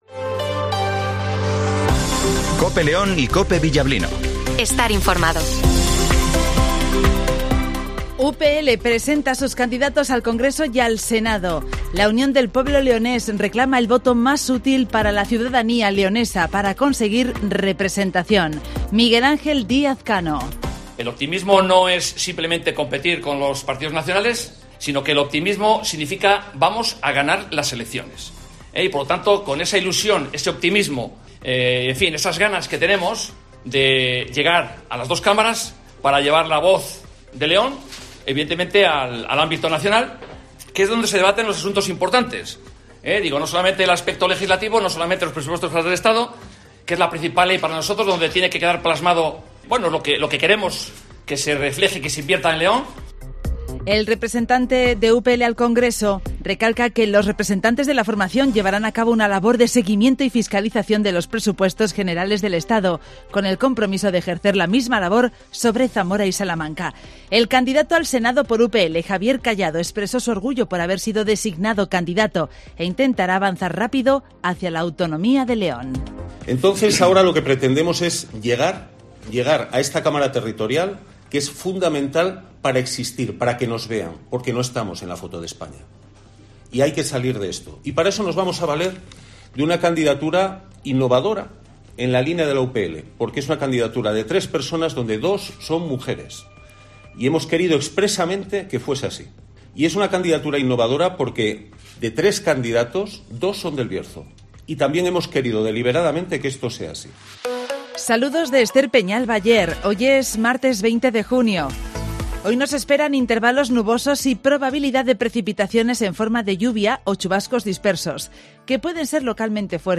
- Informativo Matinal 08:20 h